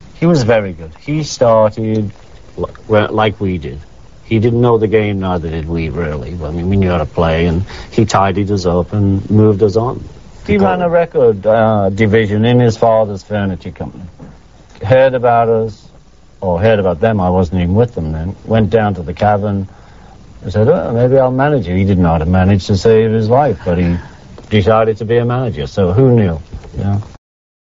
Ringo appeared on CNN's Larry King Live to promote his new album Vertical Man on June 17,1998.